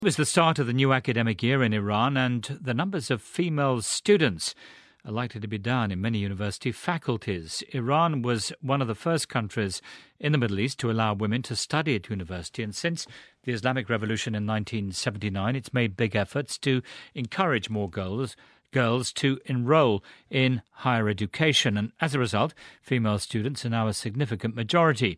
【英音模仿秀】数十载努力突遭禁 听力文件下载—在线英语听力室